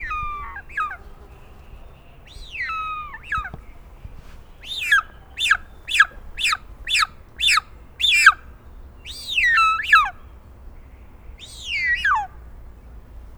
kigyaszolyv00.13.wav